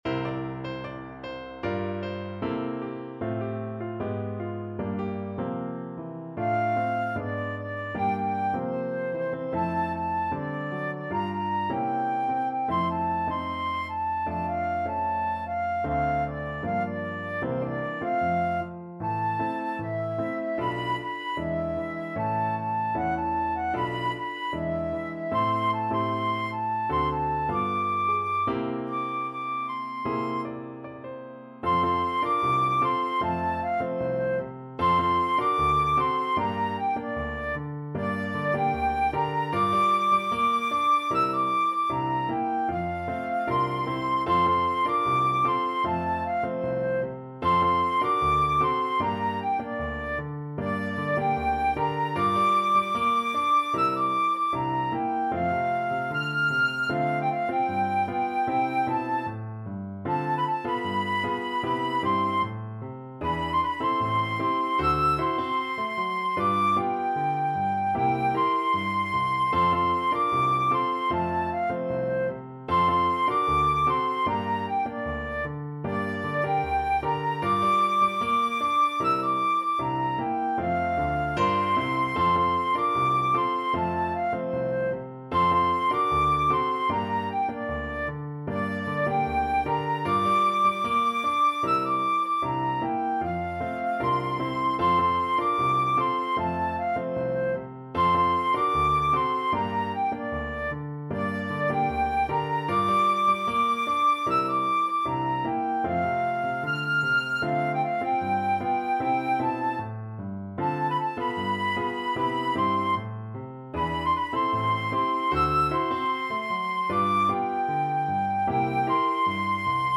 Flute version
Pop (View more Pop Flute Music)